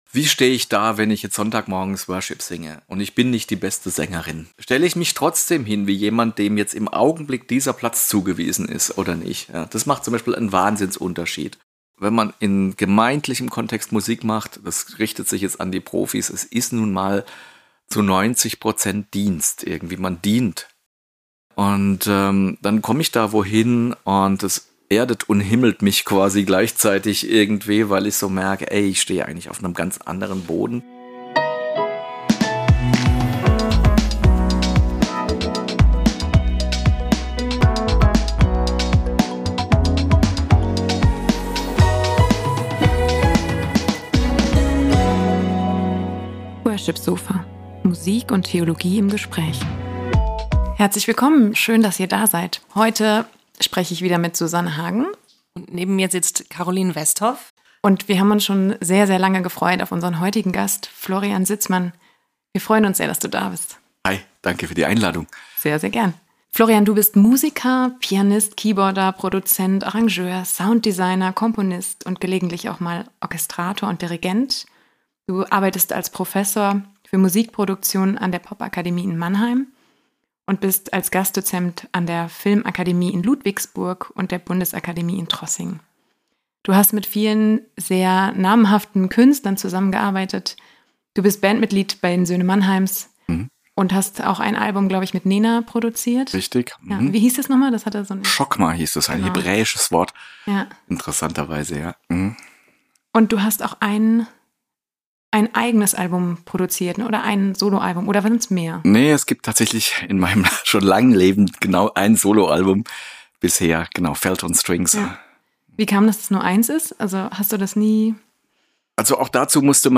Er ist als Musiker, Musikproduzent und Professor für Musikproduktion bekannt. Ein besonderer Höhepunkt der Folge ist Florian Sitzmanns Klavierimprovisation am Ende.